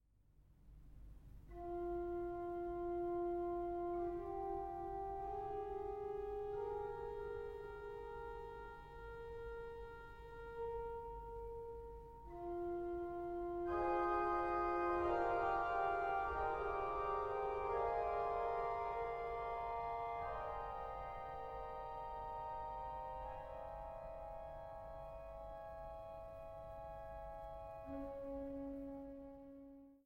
Cymbelstern